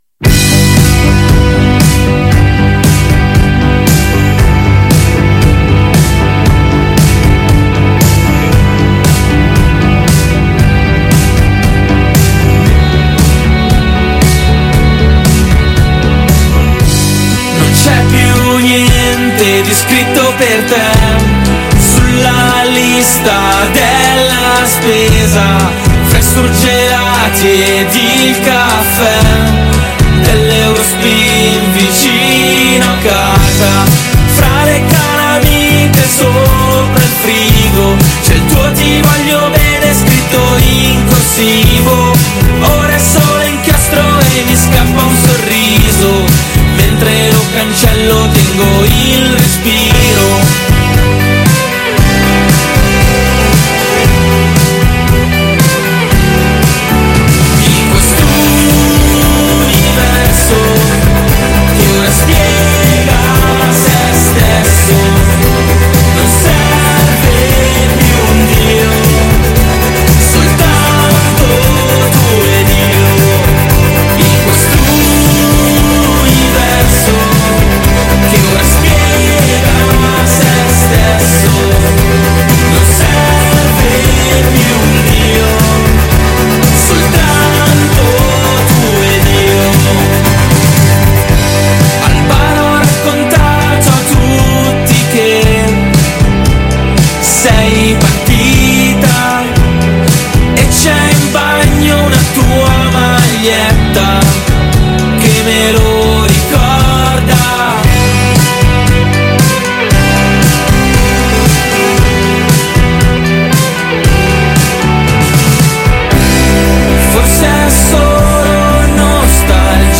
Intervista Gli occhi degli altri | 14-11-22 | Radio Città Aperta